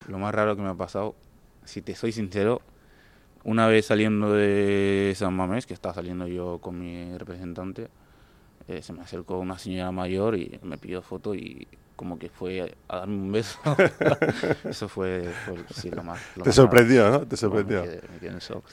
Adama Boiro en una entrevista con Radio Popular em Lezama / RADIO POPULAR - HERRI IRRATIA